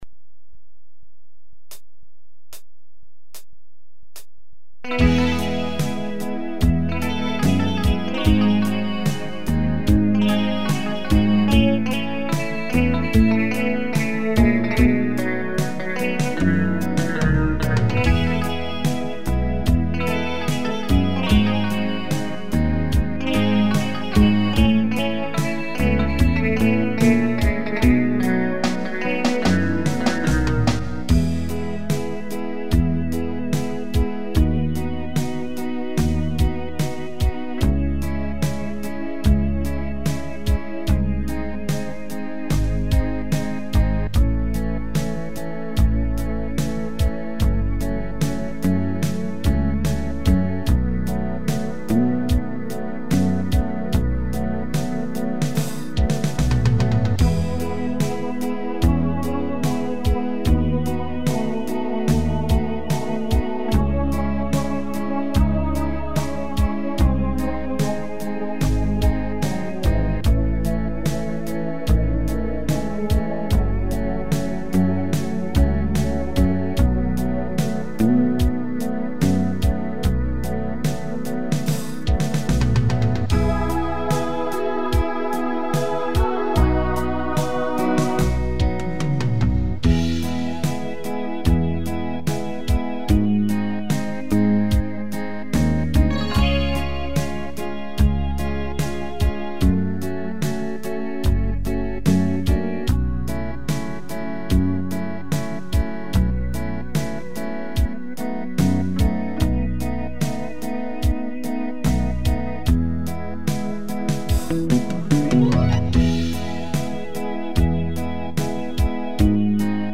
минусовка версия 228592